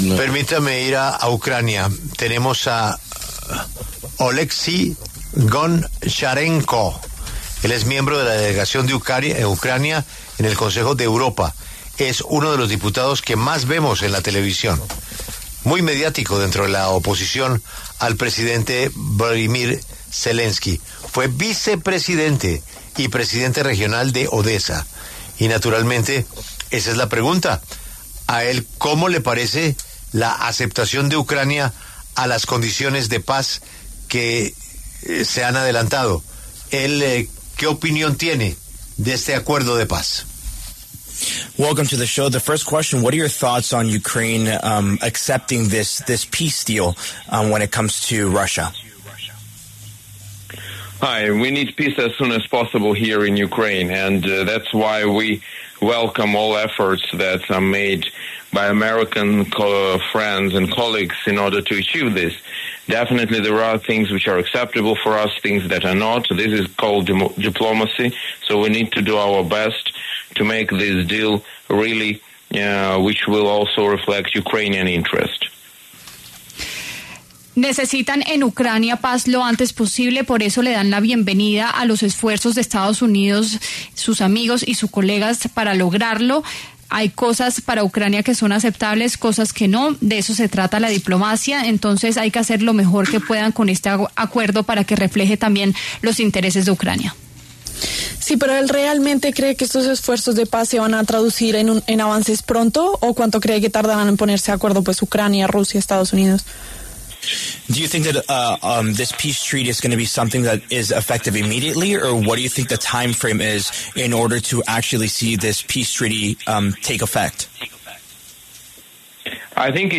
En los micrófonos de La W, con Julio Sánchez Cristo, habló Oleksiy Goncharenko, miembro de la delegación de Ucrania en la Asamblea Parlamentaria del Consejo de Europa, para referirse al plan de paz que ha propuesto Estados Unidos con el fin de acabar el conflicto con Rusia.